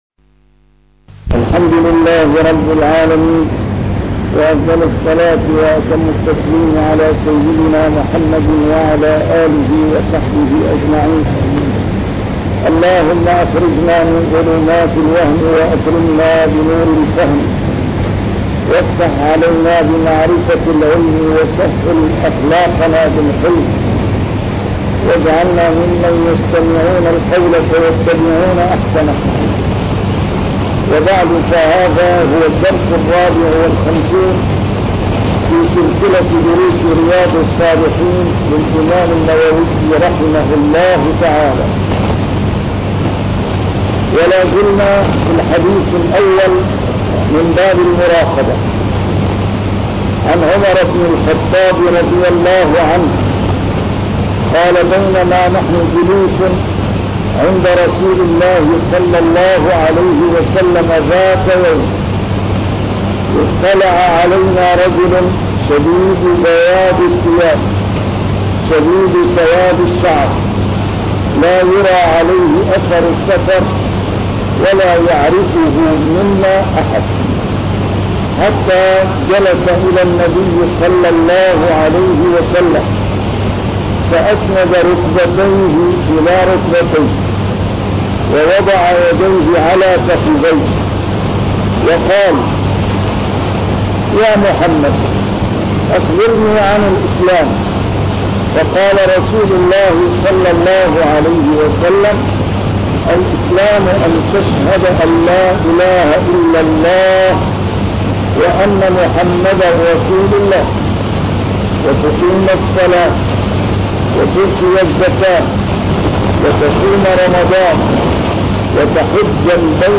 شرح كتاب رياض الصالحين - A MARTYR SCHOLAR: IMAM MUHAMMAD SAEED RAMADAN AL-BOUTI - الدروس العلمية - علوم الحديث الشريف - 54- شرح رياض الصالحين: المراقبة